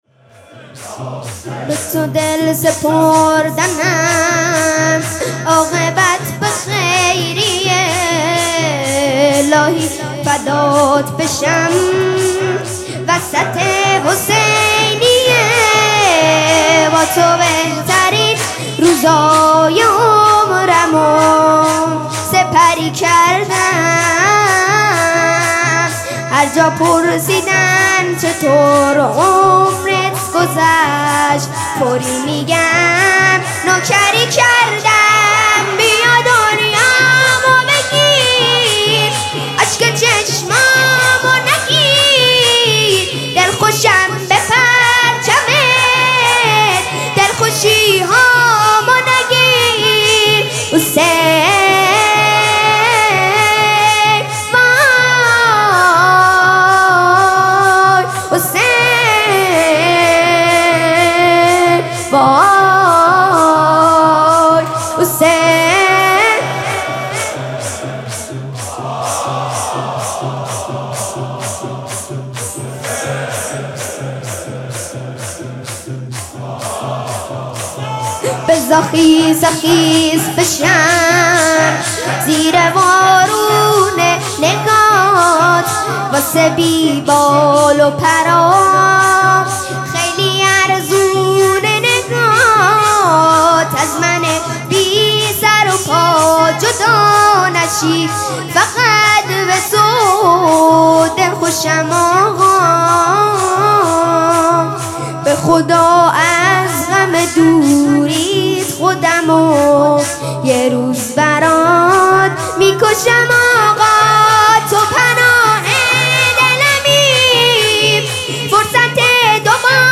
مراسم سوگواری شهادت حضرت زینب سلام الله علیها
شور